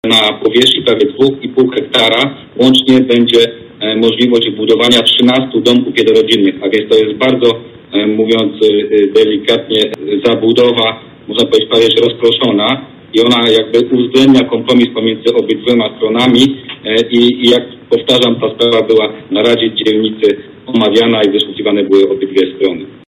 Tłumaczy wiceprezydent Zielonej Góry, Krzysztof Kaliszuk: